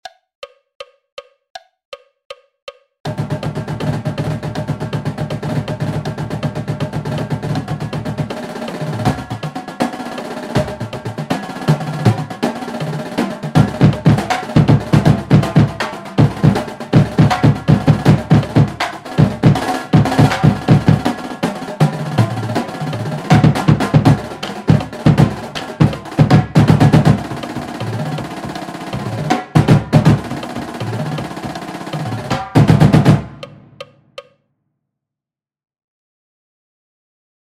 Snare Exercises
Brolls (Triplet Rolls) /